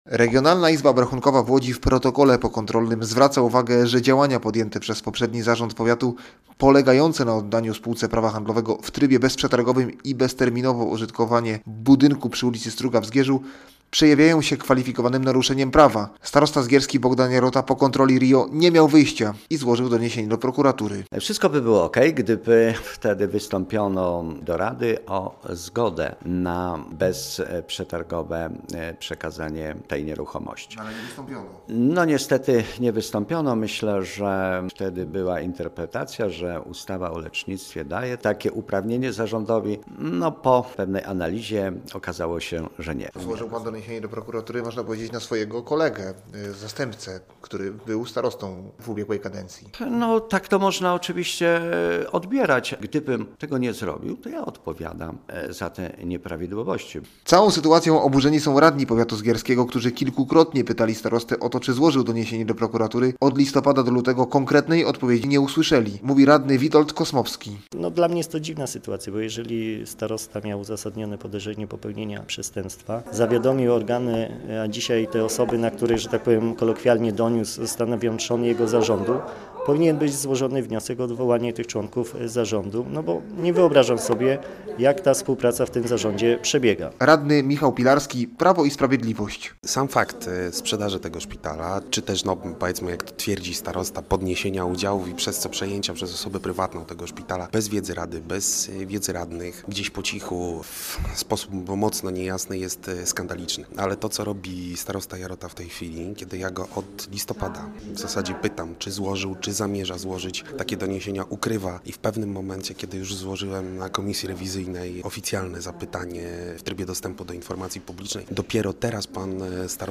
Posłuchaj relacji naszego reportera i dowiedz się więcej: Nazwa Plik Autor Doniesienie do prokuratury ws.